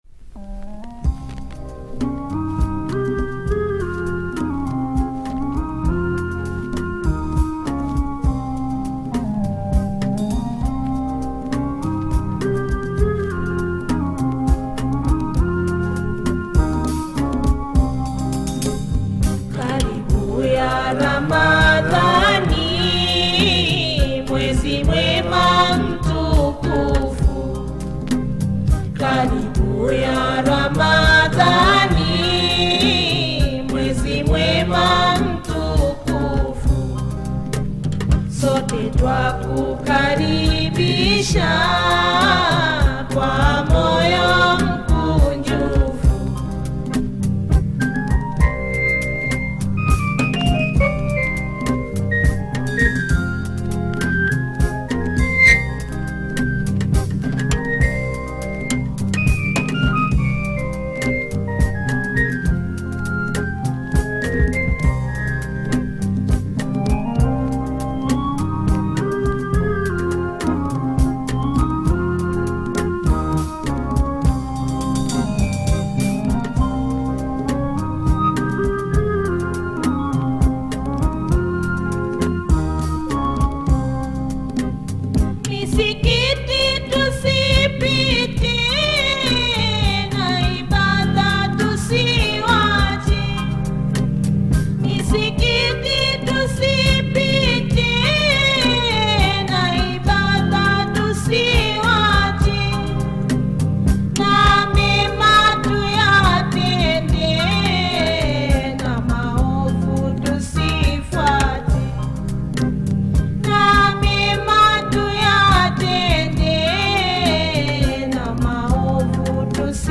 classic taarab tunes